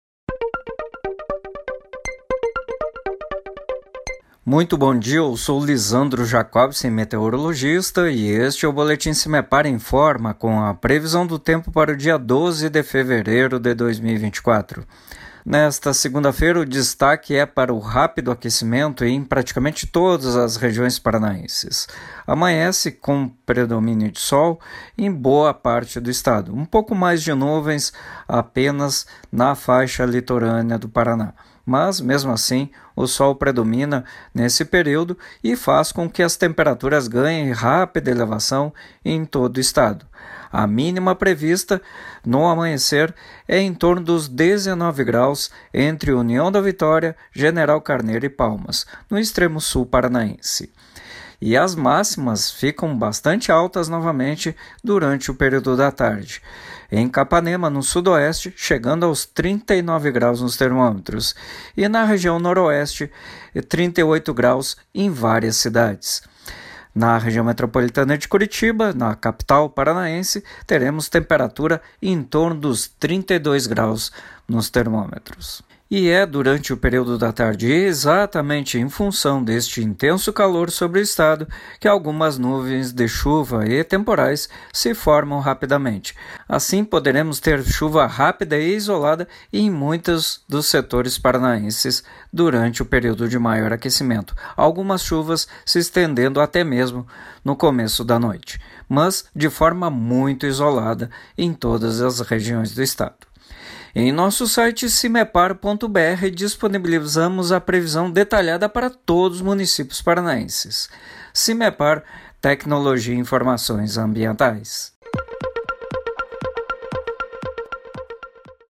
Meteorologista do Simepar